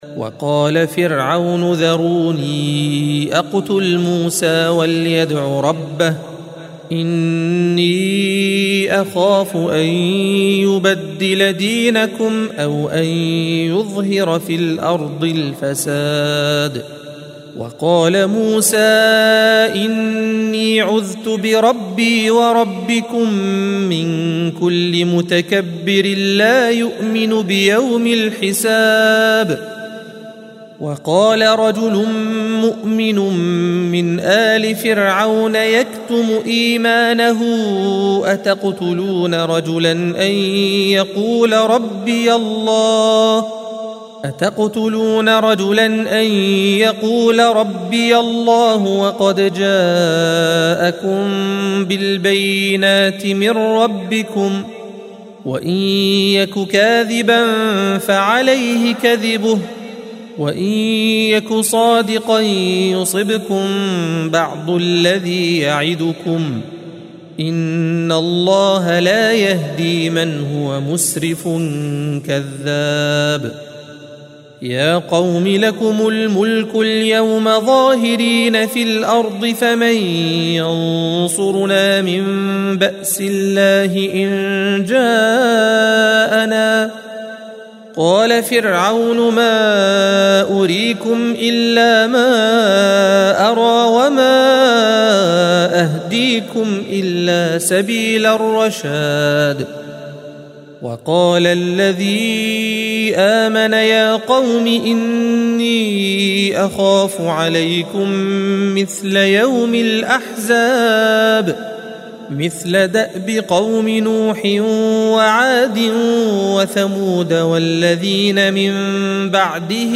الصفحة 470 - القارئ